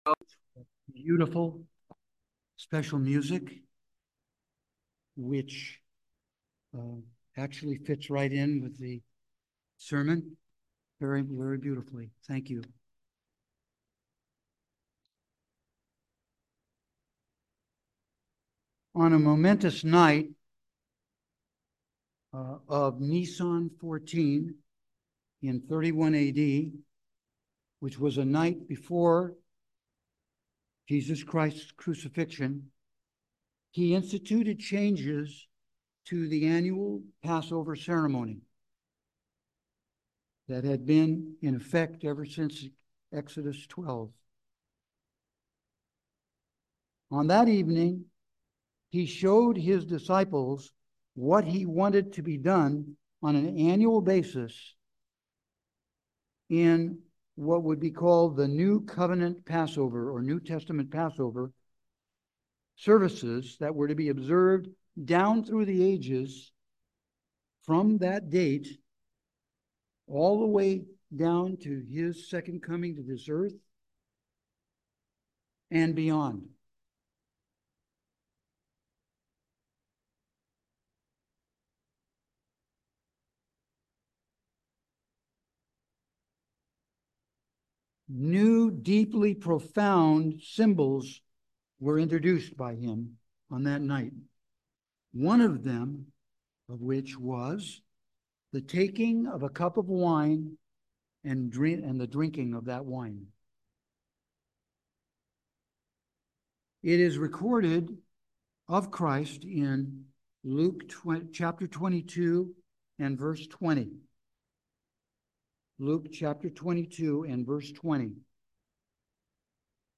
Sermons
Given in Petaluma, CA San Francisco Bay Area, CA